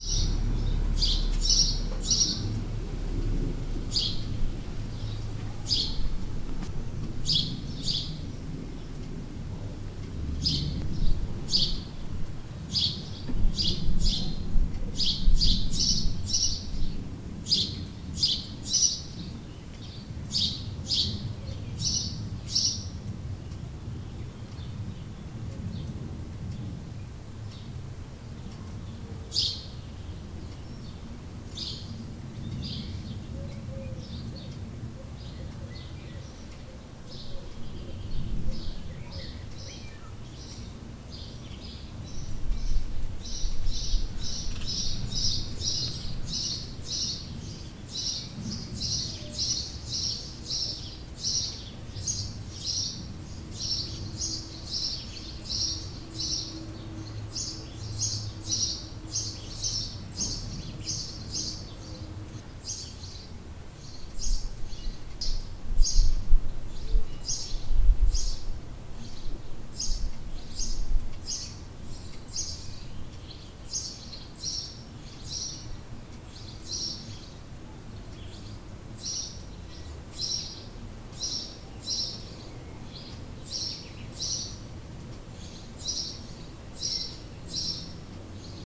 A veréb hangja
Haussperling2008.ogg.mp3